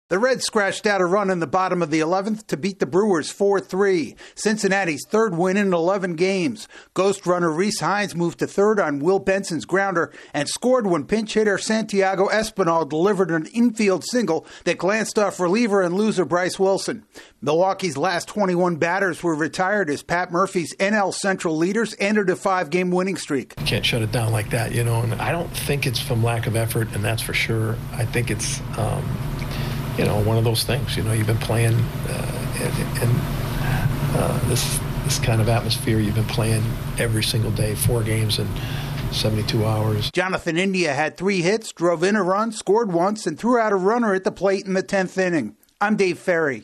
The Reds cool off the NL Central leaders. AP correspondent